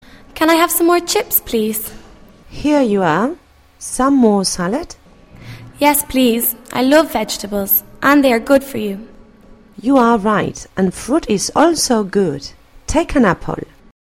Descripci�n: El video representa la conversaci�n entre varias personas (protagonistas que aparecen y texto asociado se muestra m�s abajo).